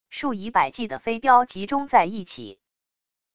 数以百计的飞镖集中在一起wav音频